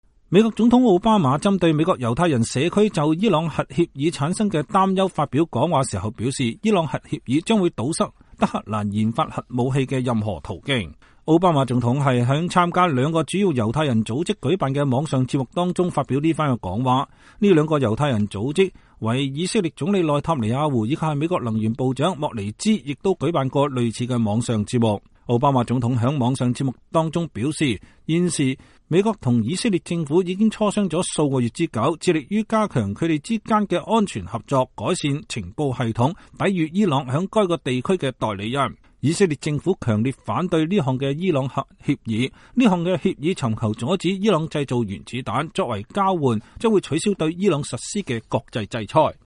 奧巴馬總統就伊朗核協議向猶太人團體發表演說
美國總統奧巴馬針對美國猶太人社區就伊朗核協議產生的擔憂發表講話時表示，伊朗核協議將堵塞德黑蘭研發核武器的任何途徑。奧巴馬總統是在參加兩個主要猶太人組織舉辦的網上節目中說這番話的。